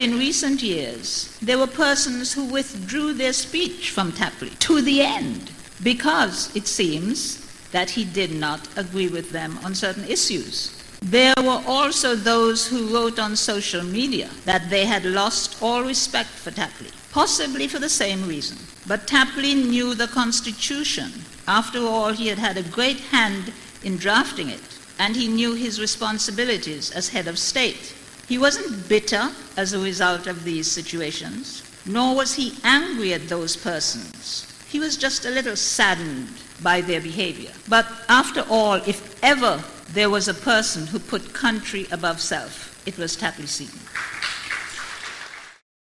His state service was held at the Zion Moravian Church in St. Kitts.
Here is an excerpt from the service, as this was carried live on VON Radio: